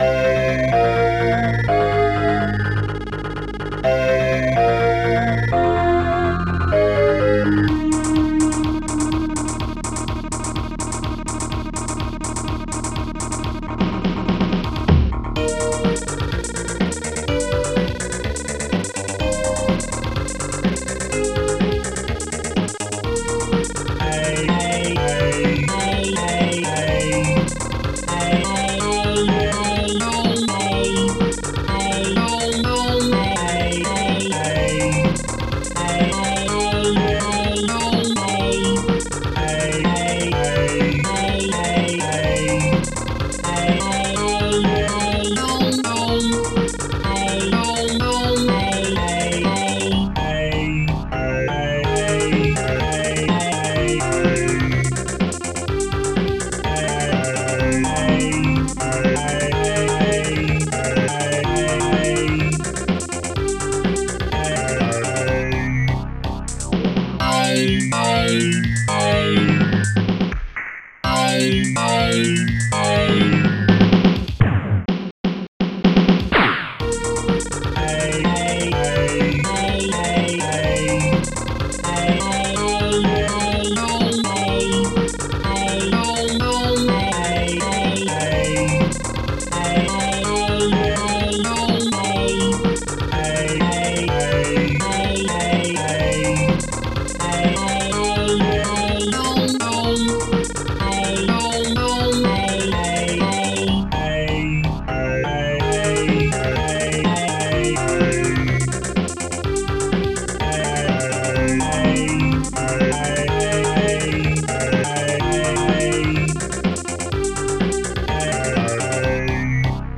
Protracker and family
st-01:funkbass
st-01:bassdrum2
st-01:snare2
st-01:hihat2
st-01:strings2
st-01:panflute
st-01:celeste